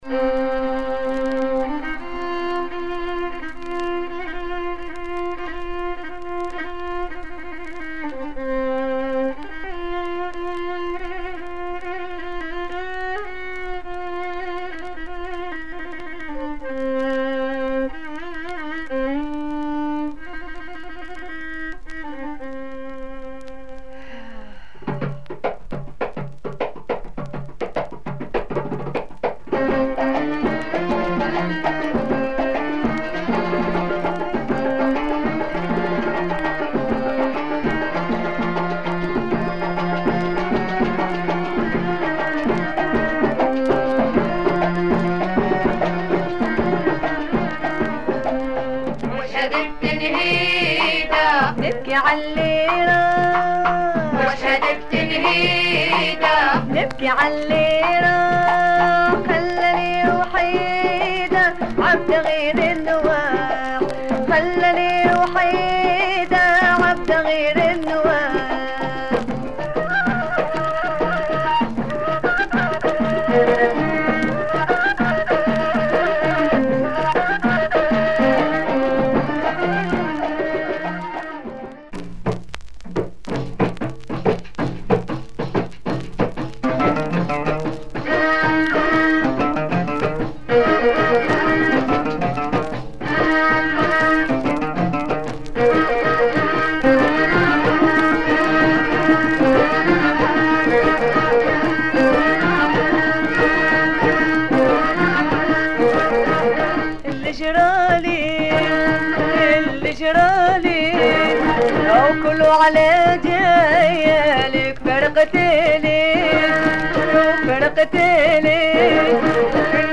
Raw Algerian 70's proto rai.